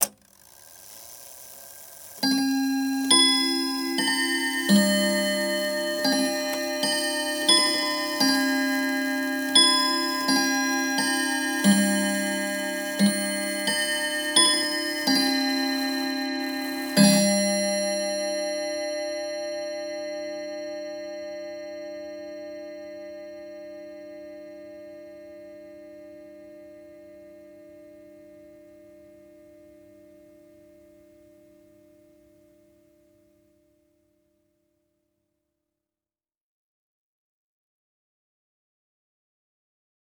Clock Hour Chime.wav